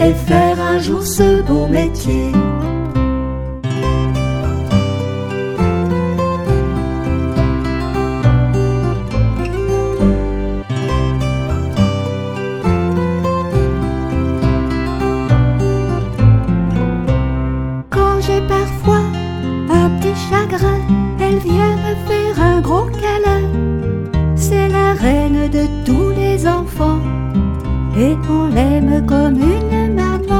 Musicien. Ens. voc. & instr.